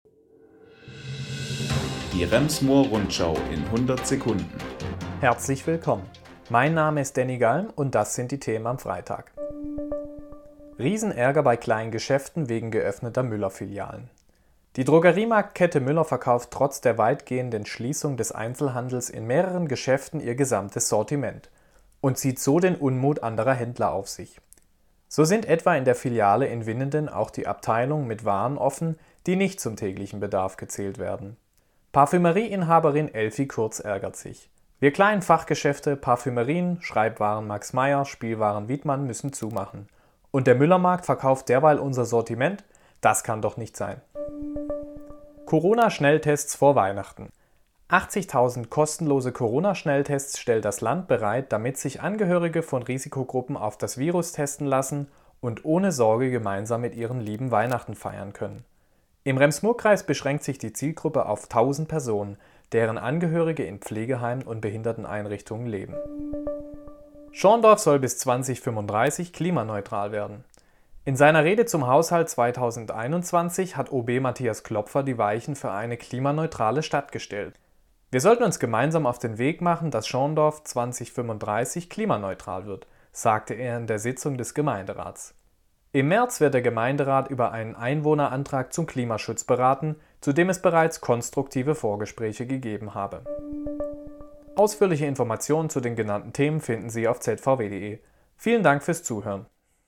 Die wichtigsten Nachrichten des Tages